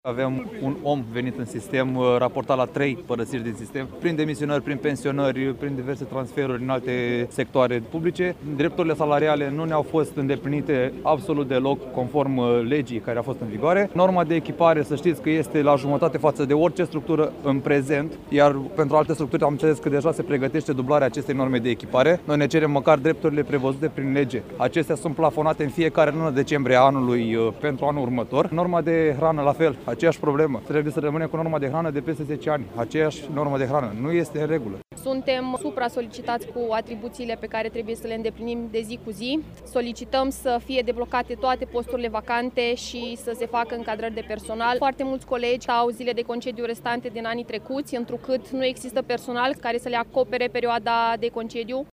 21-martgie-rdj-12-Vox-protestatari.mp3